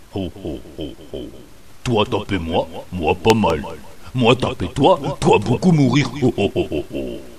Parfois au cours d'un combat, ils invectivent leurs adversaires avec une voix tonitruante qui bourdonne dans les oreilles.
En effet, leur grammaire semble constituée de grognements, rires et verbes (de 2 syllabes maximum) suivis d'adjectifs.
troll_commun.mp3